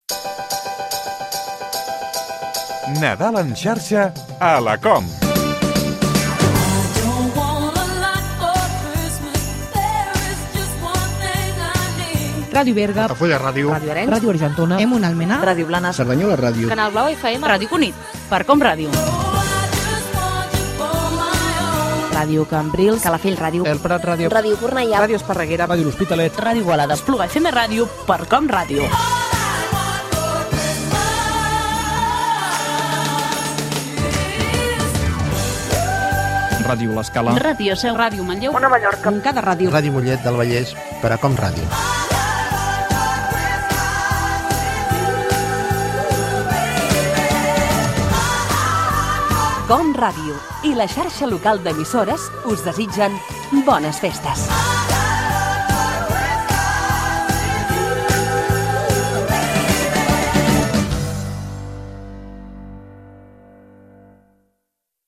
Dues promocions de "Nadal en xarxa a la COM" amb identificacions de diverses emissores de la Xarxa Local d'Emissores